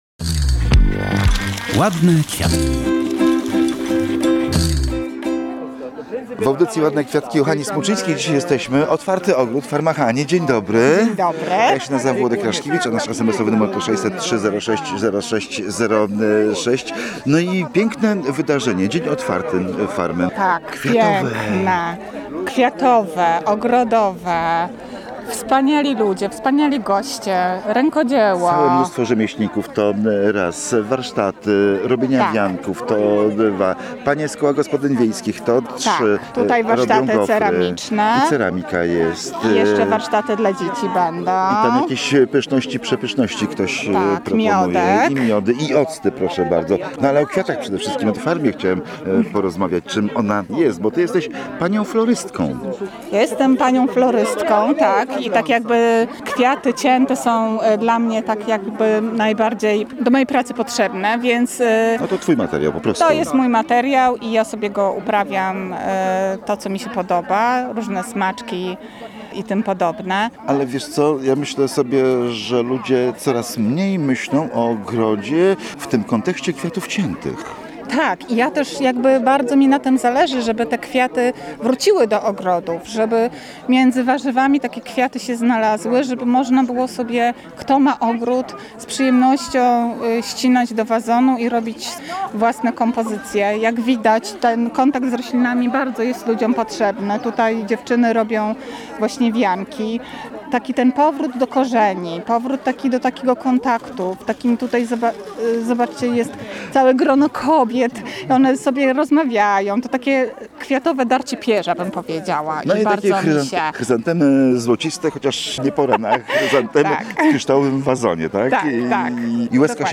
Spotkanie to było okazją do rozmów z wieloma osobami zajmującymi się ogrodnictwem i do zaproszenia na kolejne tego rodzaju wydarzenia.